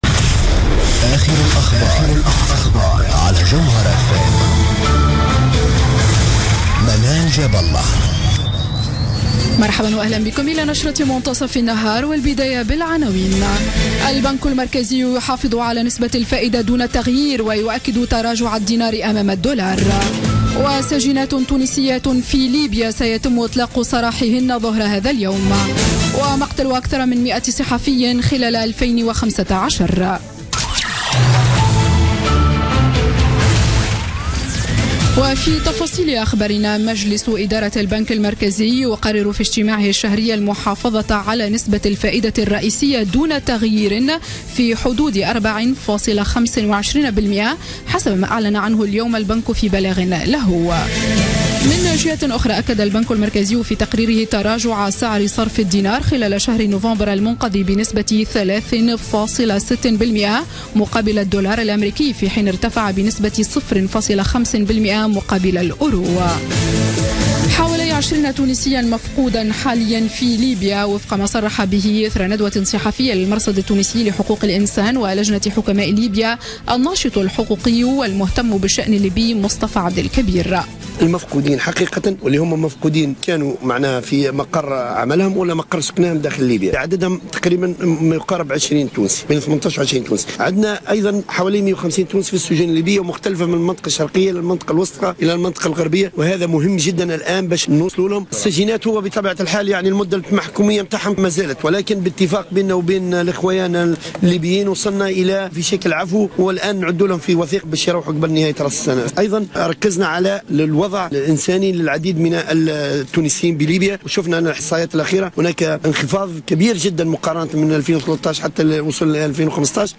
نشرة أخبار منتصف النهار ليوم الثلاثاء 29 ديسمبر 2015